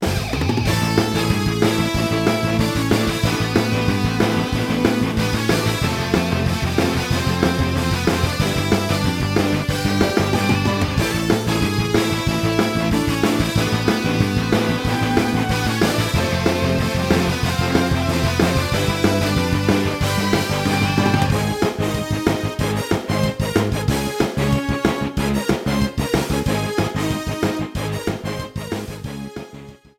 Trimmed and fade out
Fair use music sample